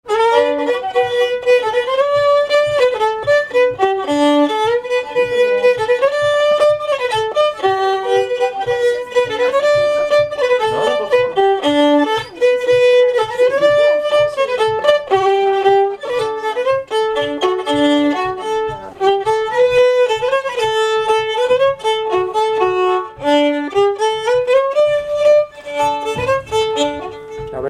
Mémoires et Patrimoines vivants - RaddO est une base de données d'archives iconographiques et sonores.
Polka
danse : polka
Pièce musicale inédite